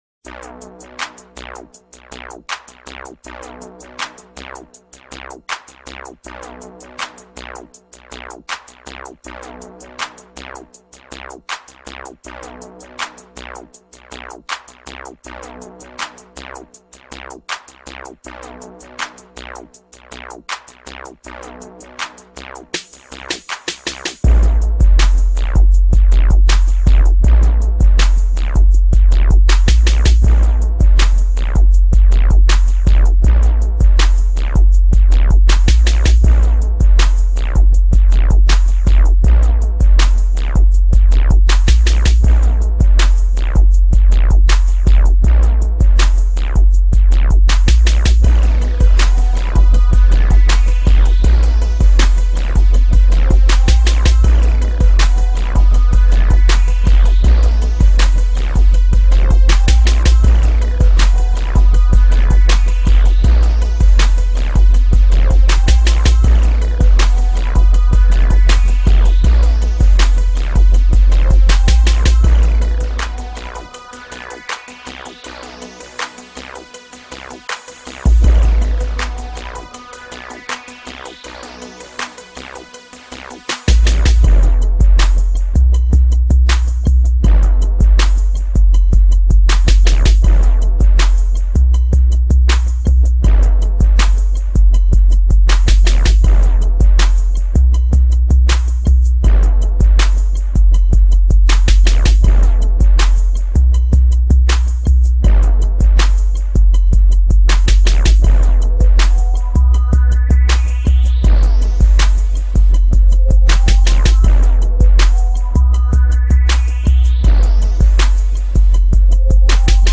EXTREME BASS SONG.mp3